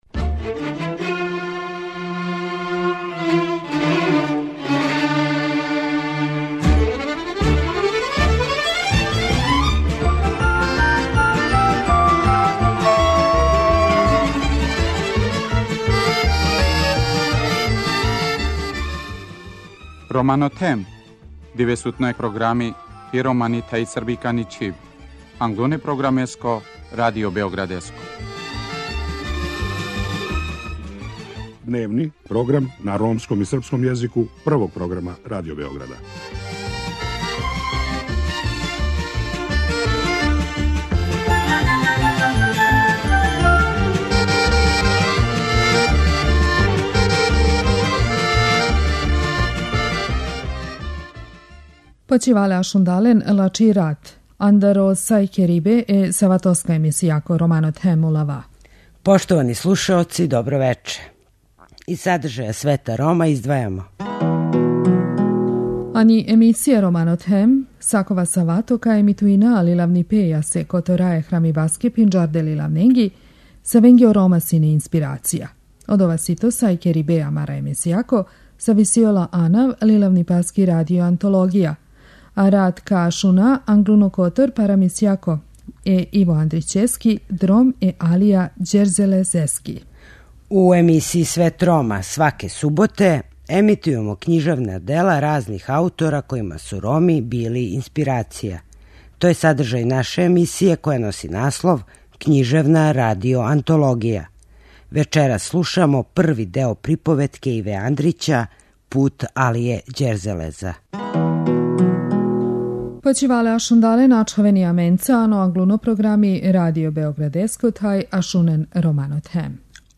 Вечерас слушамо први део приповетке Иве Андрића – Пут Алије Ђерзелеза.